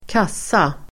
Ladda ner uttalet
Uttal: [²k'as:a]